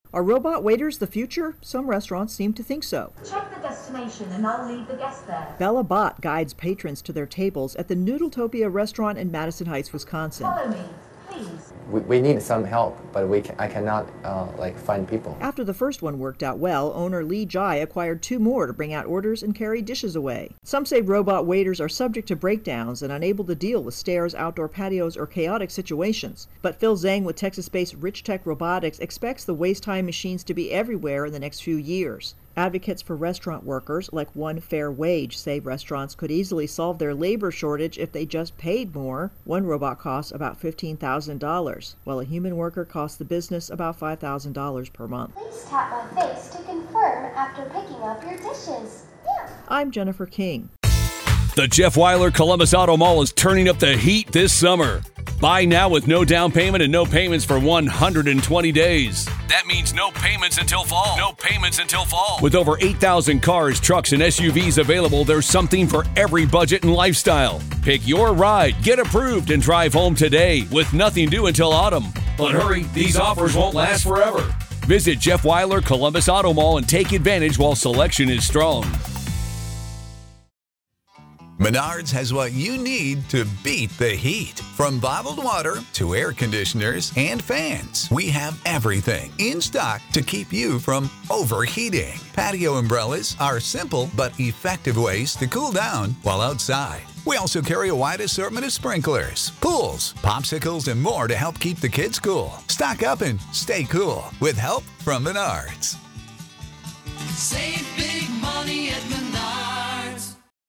reports on Robot Waiters